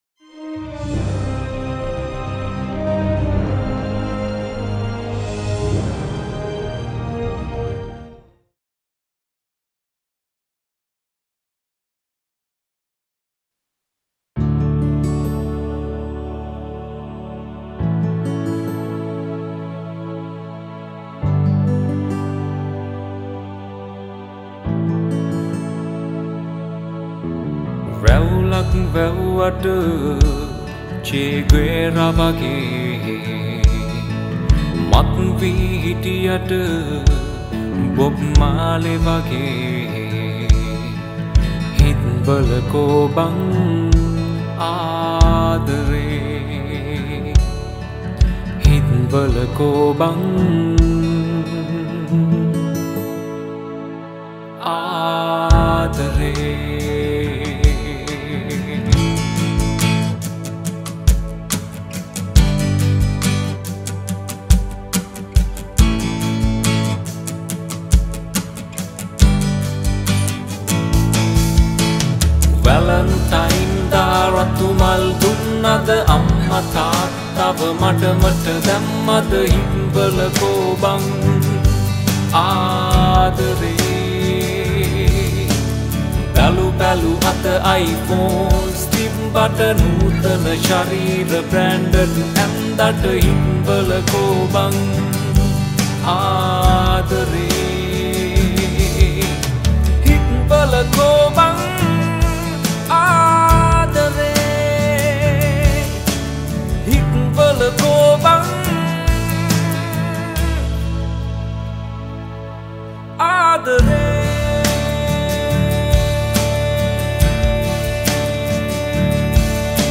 Sinhala Songs
This is a very emotional song about love.